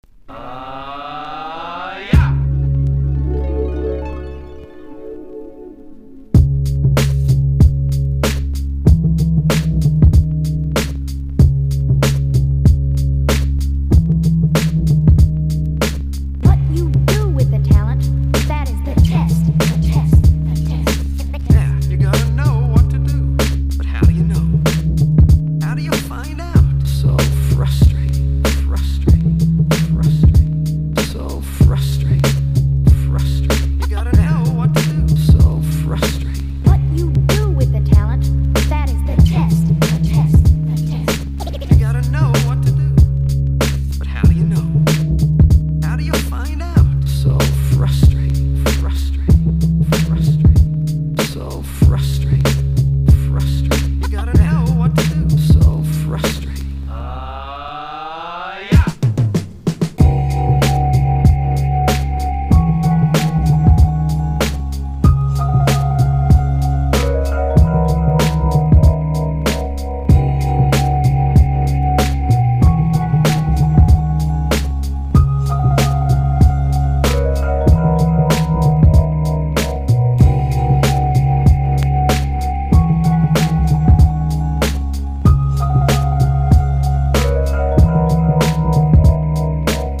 チルアウトな感性と透明感溢れるハーモニーの織りなす美しいサウンドスケープ！
UNDERGROUND HIPHOP# HEADZ / ELECTRONICA / CHILOUT
• 盤面 : EX+ (美品) キズやダメージが無く音質も良好
タイト・ビートにファットな声ネタを交えた
穏やかなグルーヴの合間から浮かび上がるヴァイヴの音色がたまりません！